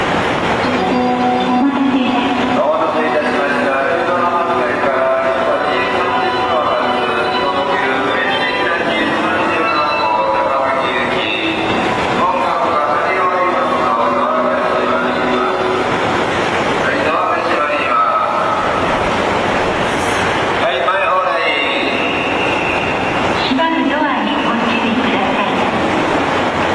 しかし、特急ホーム16,17番線には発車メロディーが導入されています。
Cielo Estrellado 壮大な感じの発車メロディです。